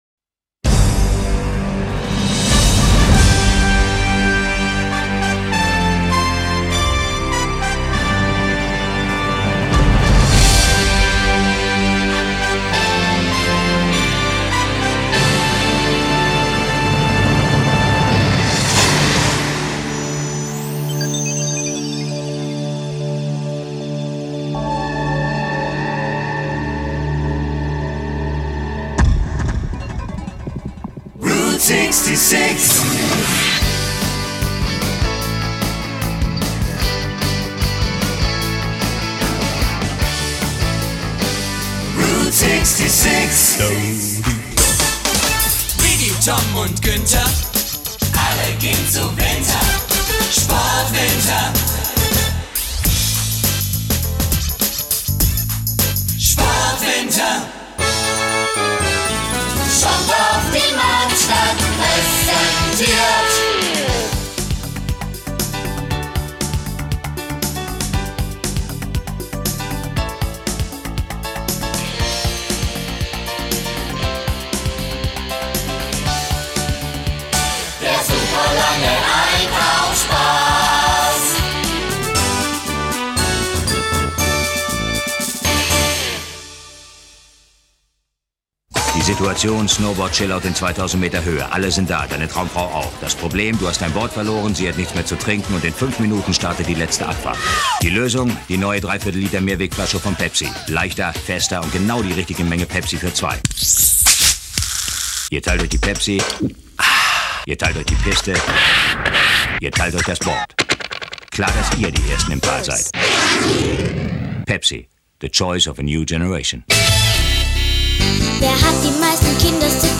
Werbetrailer.